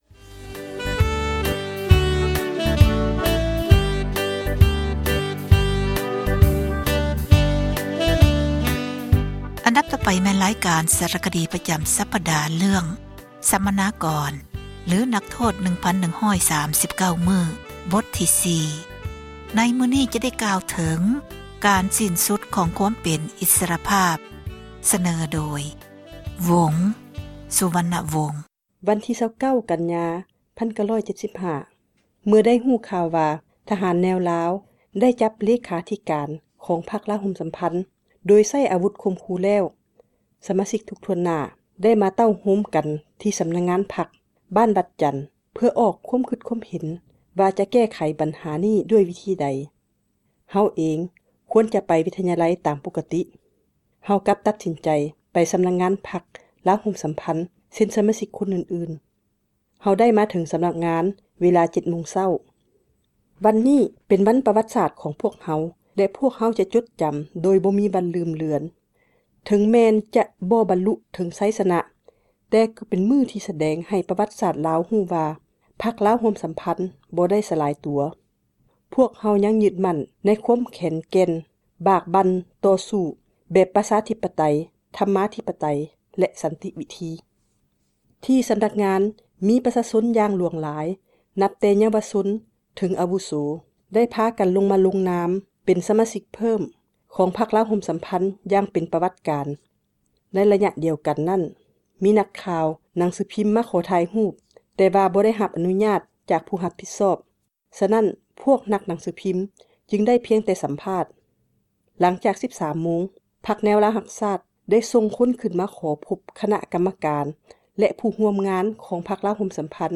ສາຣະຄະດີ ປະຈຳ ສັປດາ ເຣື້ອງ ສັມມະນາກອນ ຫຼື ນັກໂທດ 1,139 ມື້. ໃນມື້ນີ້ ຈະ ໄດ້ກ່າວເຖິງ ການສິ້ນສຸດ ອິສຣະພາບ ຂອງ ຄວາມເປັນ ມະນຸສ.